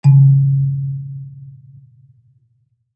kalimba_bass-C#2-ff.wav